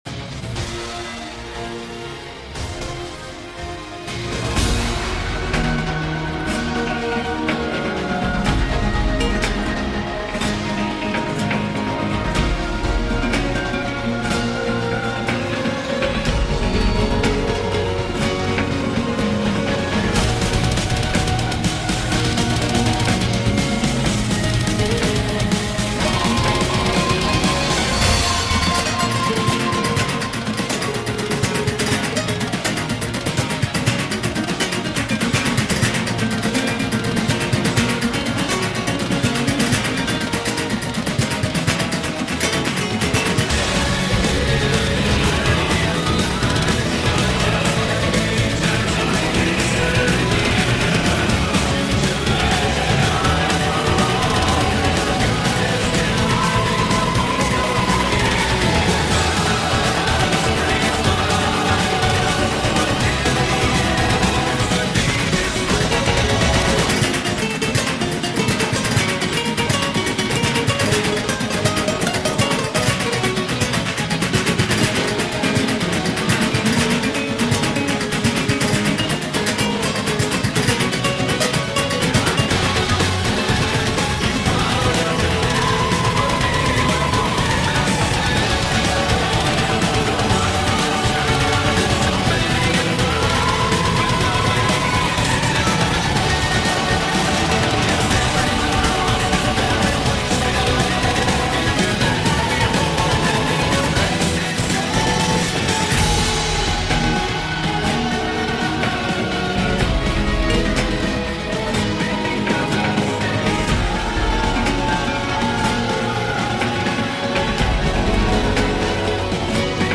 Music / Game Music
:D. A bit chaotic, but still cool.